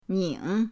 ning3.mp3